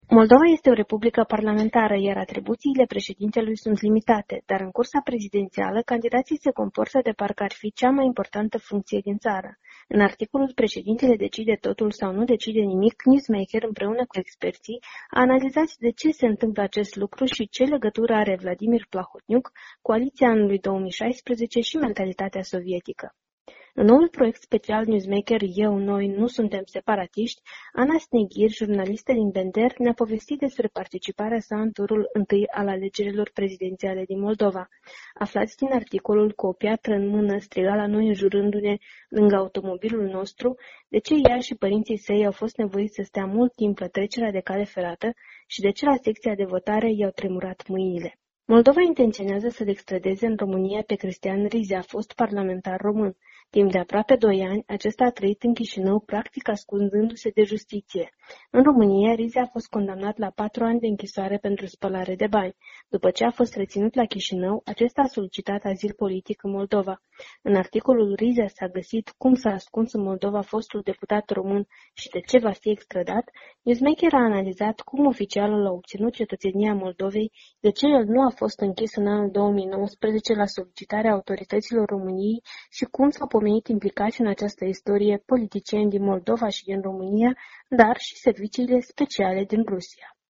Invitata este